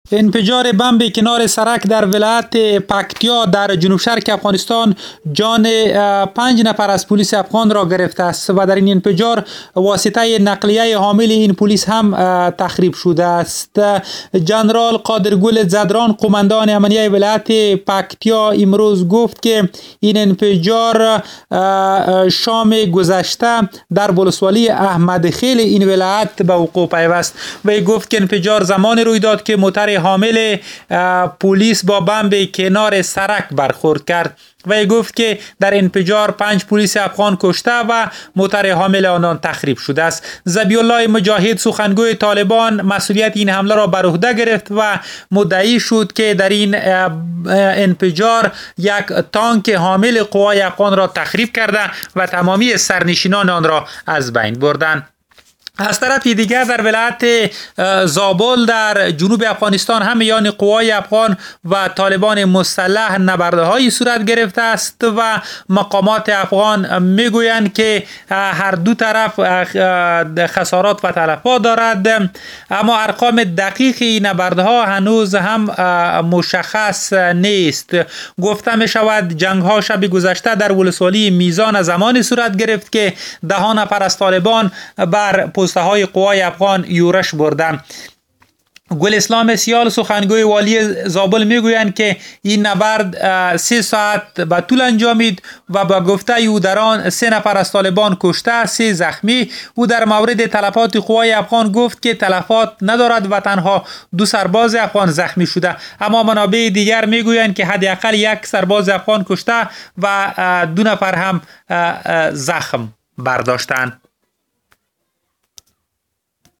گزارش ؛ کشته شدن ۵ پلیس در انفجار شرق افغانستان